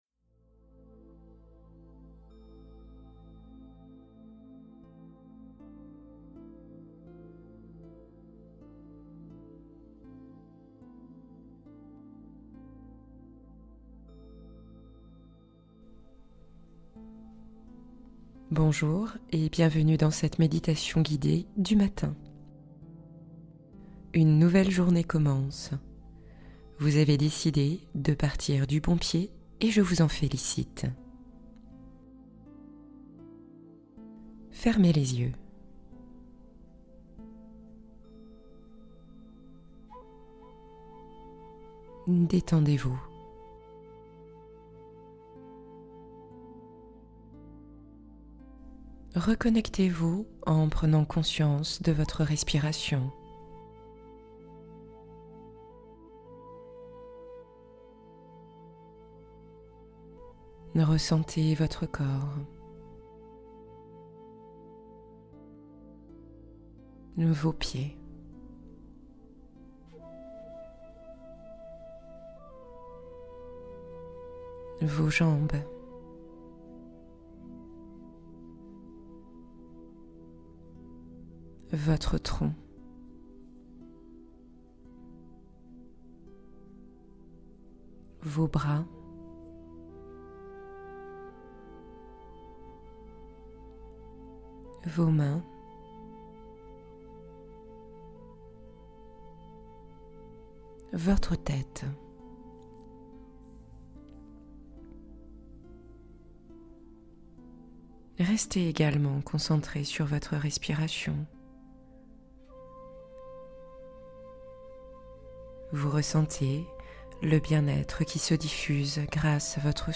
Motivez-vous instantanément dès le réveil | Méditation matinale boost énergie et inspiration